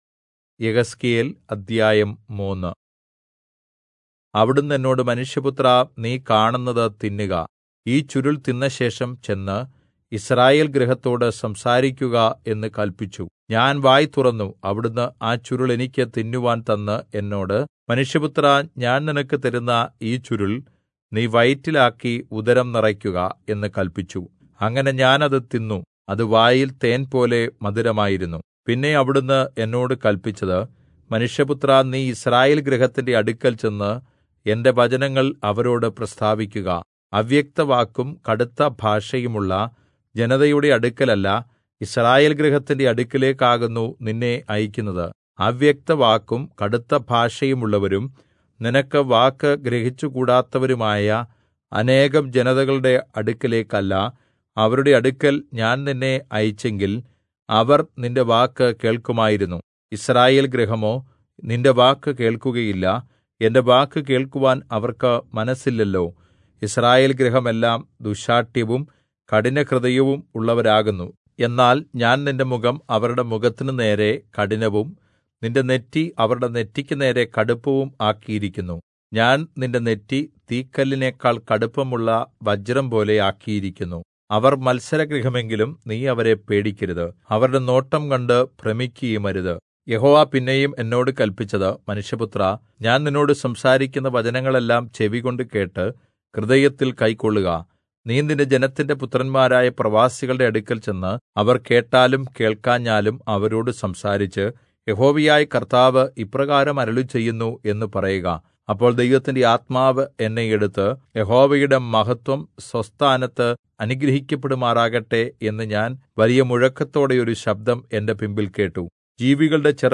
Malayalam Audio Bible - Ezekiel 17 in Irvml bible version